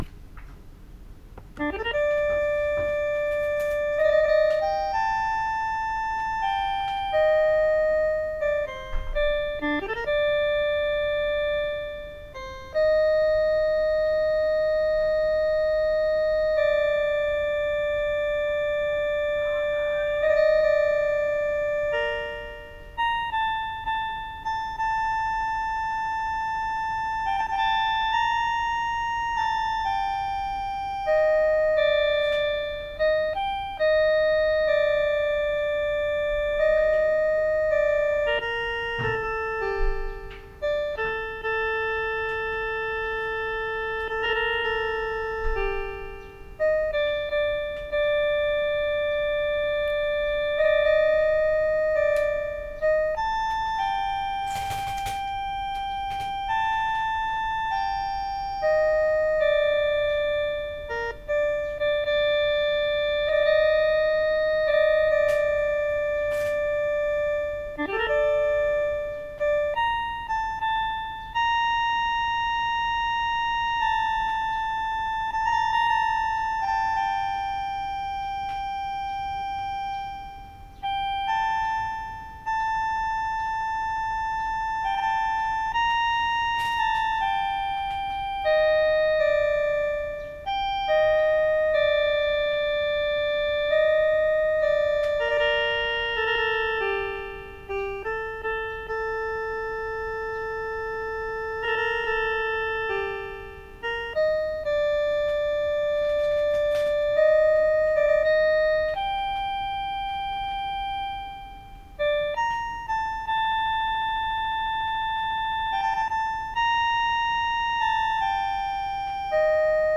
伴奏 6本   素読・範吟